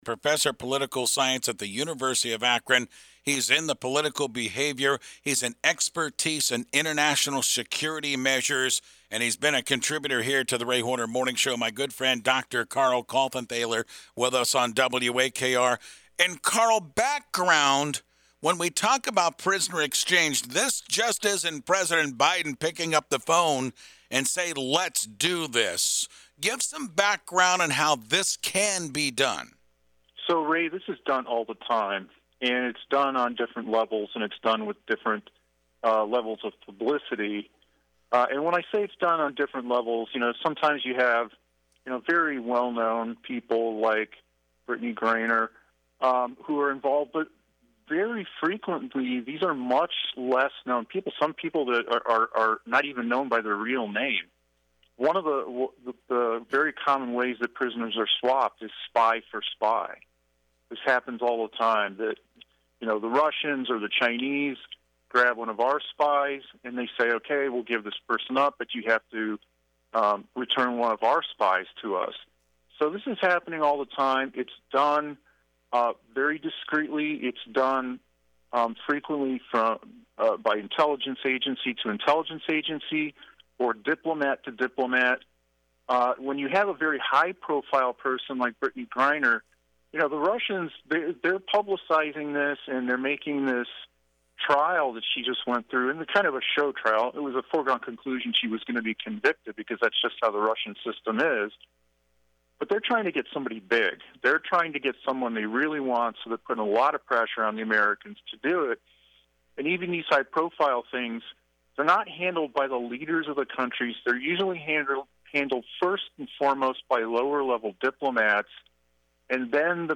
interviewed about Brittany Griner